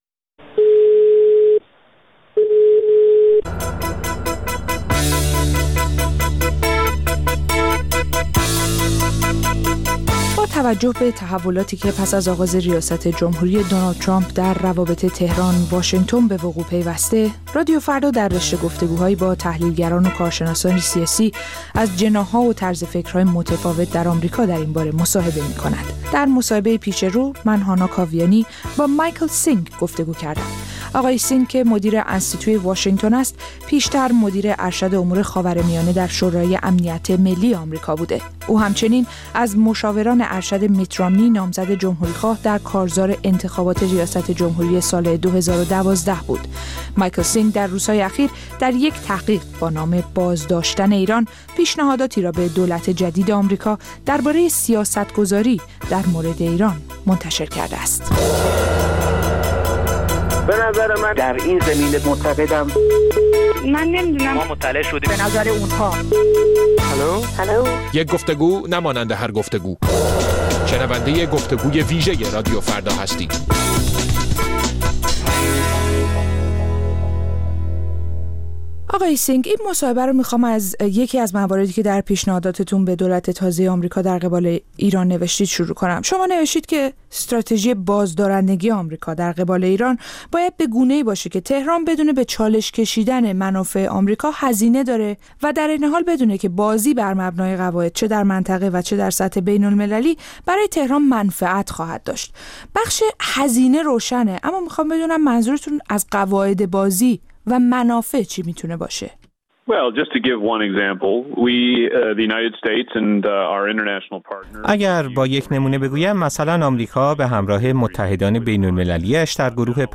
راه‌های مواجهه آمریکا با ایران در گفتگو با مایکل سینگ